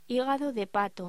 Locución: Hígado de pato